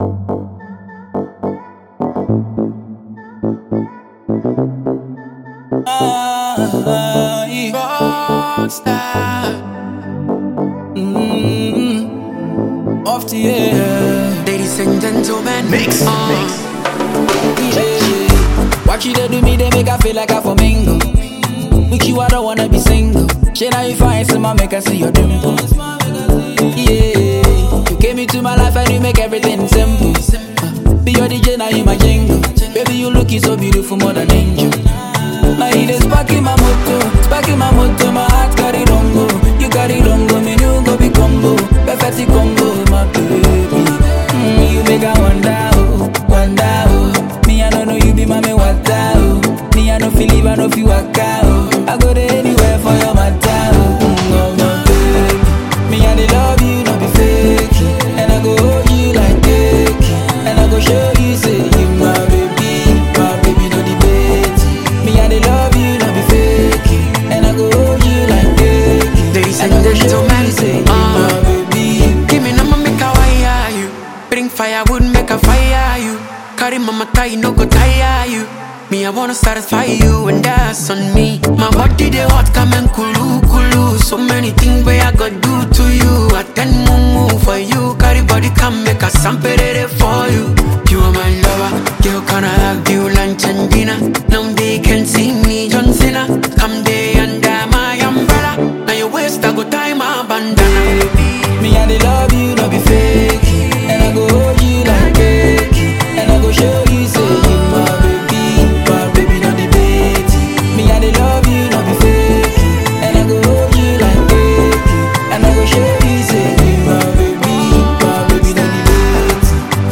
a smooth and relatable tune for all to have a feel.